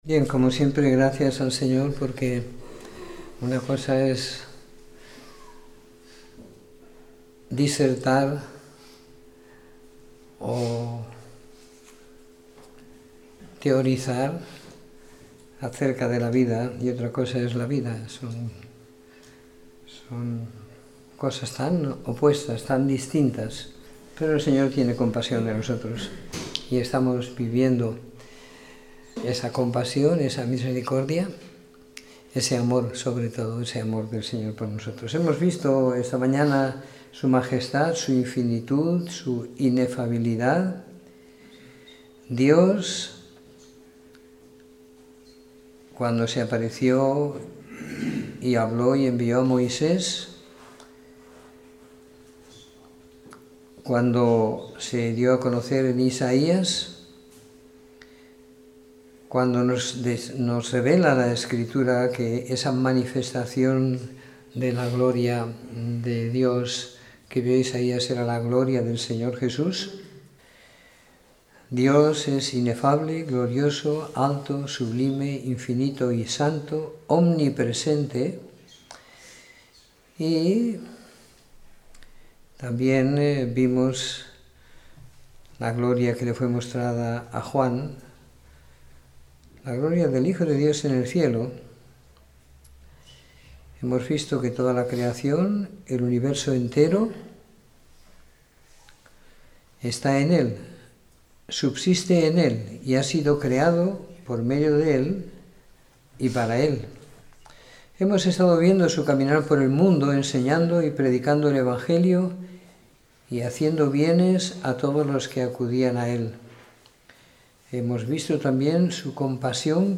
Domingo por la Tarde . 19 de Marzo de 2017